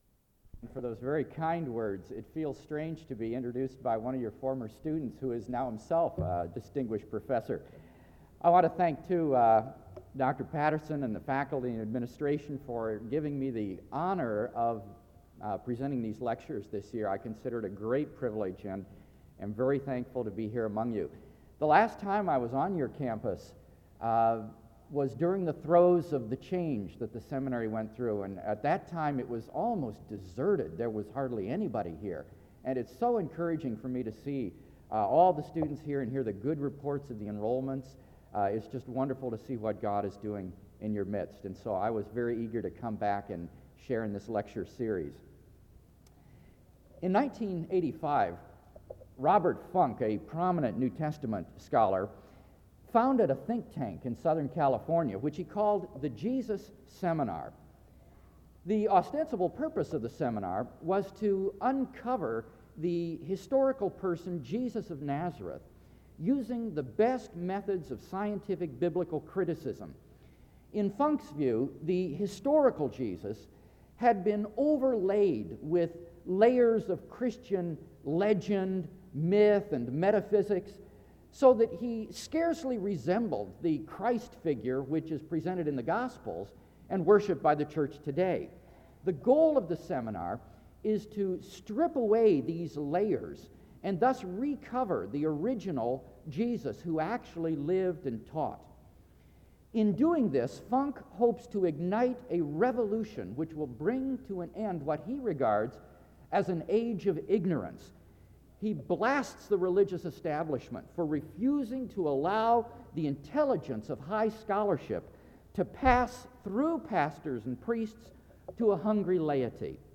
SEBTS Carver-Barnes Lecture - William Lane Craig September 24, 1996
In Collection: SEBTS Chapel and Special Event Recordings SEBTS Chapel and Special Event Recordings - 1990s Thumbnail Titolo Data caricata Visibilità Azioni SEBTS_Carver-Barnes_Lecture_William_Lane_Craig_1996-09-24.wav 2026-02-12 Scaricare